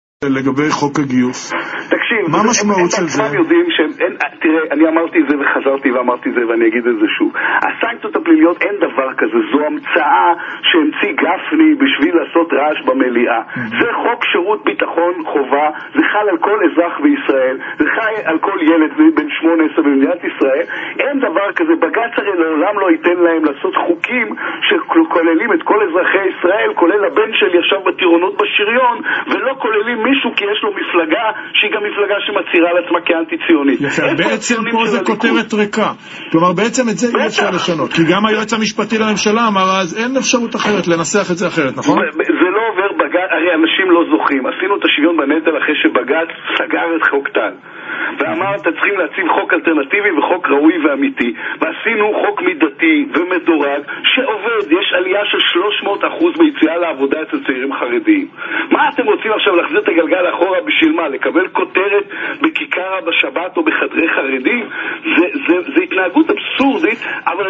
בראיון ל'רדיו ללא הפסקה' התייחס לפיד למשא ומתן הקואליציוני ותקף את ההישגים של 'יהדות התורה' ובמרכזם ביטול החוקים שלו ובמיוחד הסנקציות הפליליות על לומדי התורה.